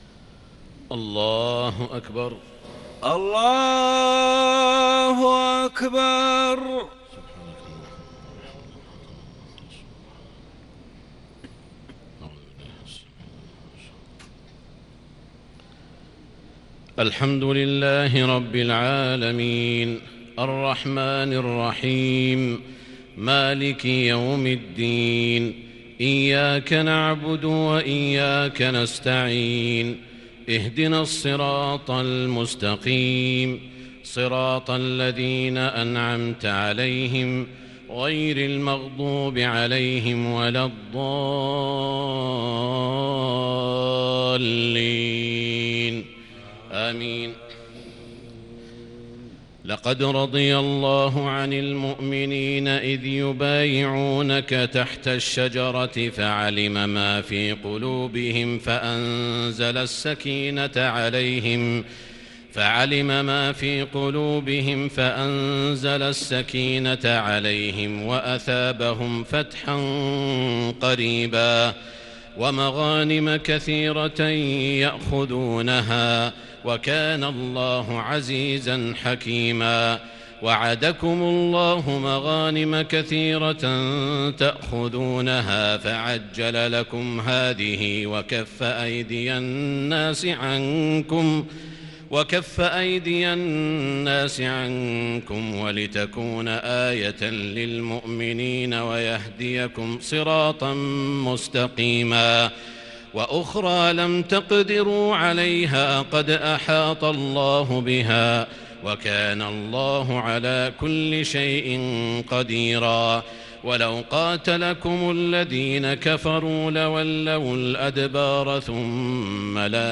تهجد ليلة 27 رمضان 1441هـ من سورة الفتح 18 إلى الذاريات - 46 | tahajud prayer from from Surah Alfath to ad-Dhariyat 1441H > تراويح الحرم المكي عام 1441 🕋 > التراويح - تلاوات الحرمين